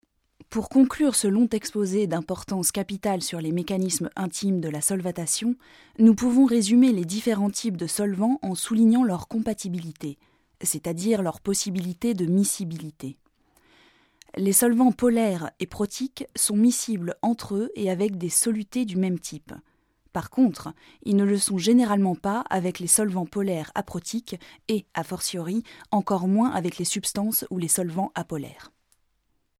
comédienne 30 ans, voix médium.
Sprechprobe: eLearning (Muttersprache):